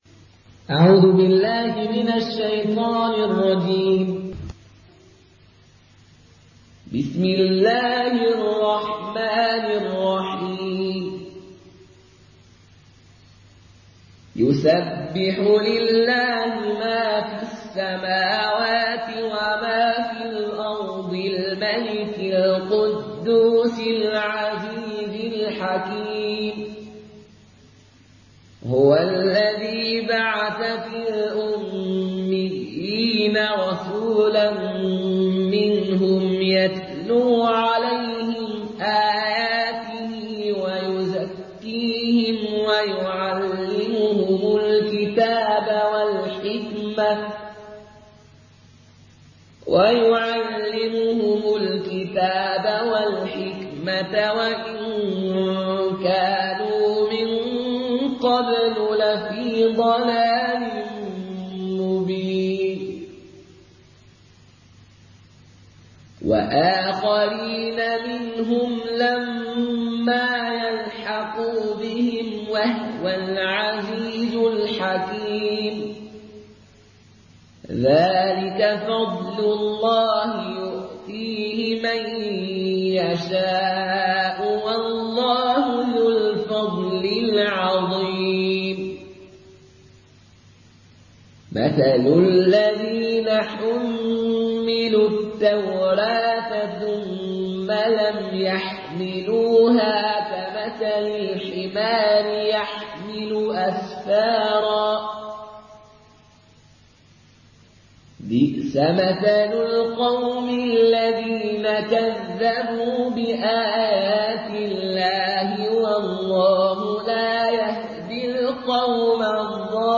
Une récitation touchante et belle des versets coraniques par la narration Qaloon An Nafi.
Murattal Qaloon An Nafi